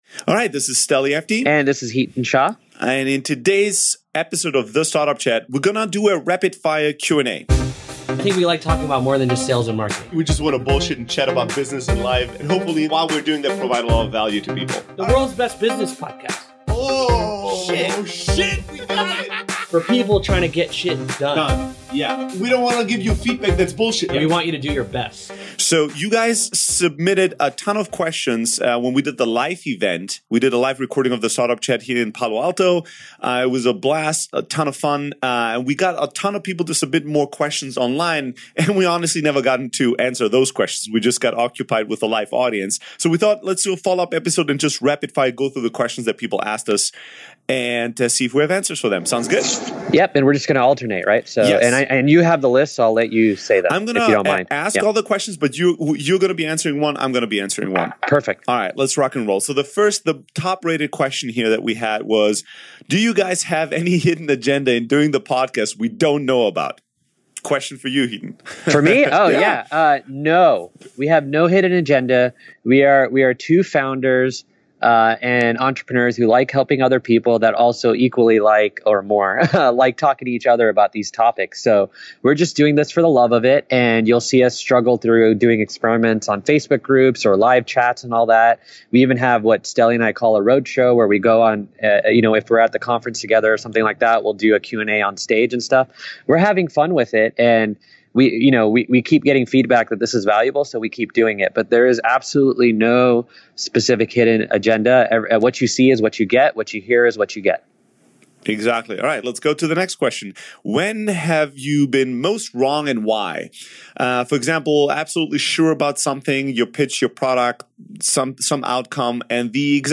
098: Startup Q&A Session